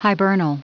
Prononciation du mot hibernal en anglais (fichier audio)
Prononciation du mot : hibernal